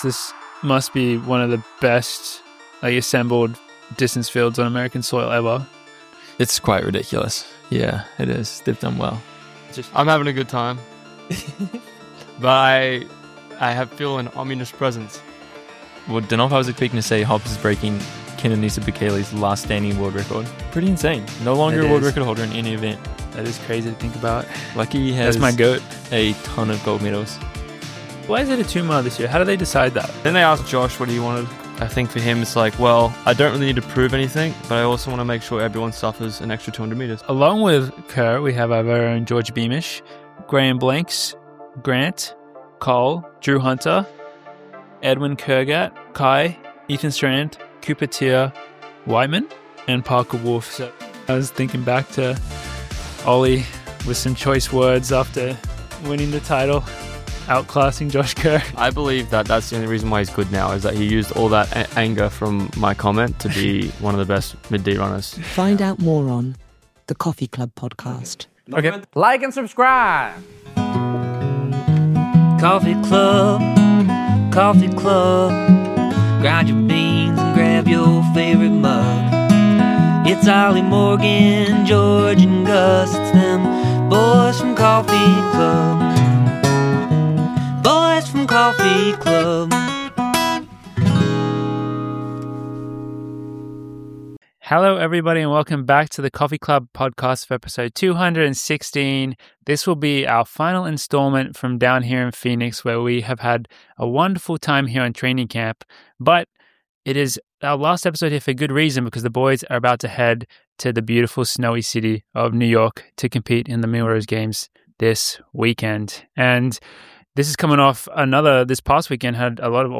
The Coffee Club Podcast is hosted by Oliver Hoare, George Beamish, and Morgan McDonald: 3 professional runners and olympians who train and live in Boulder, Colorado that compete for the On Athletics Club and On.